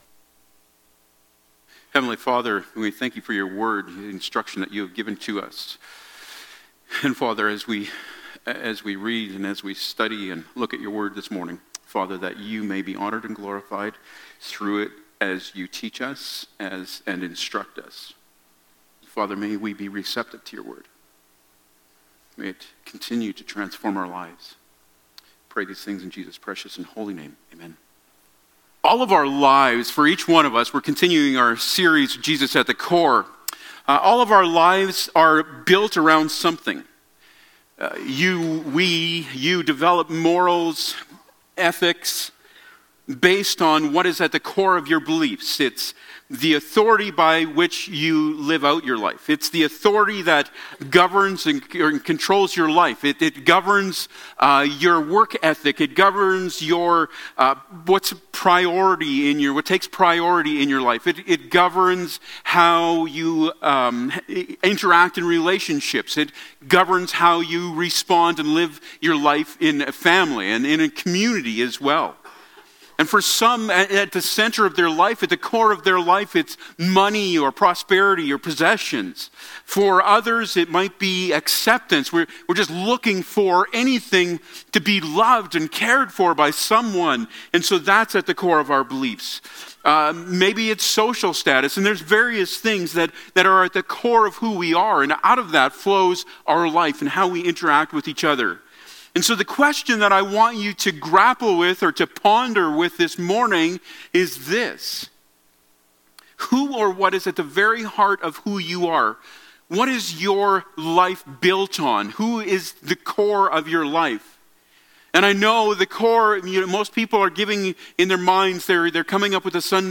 Passage: Colossians 3:1-17 Service Type: Sunday Morning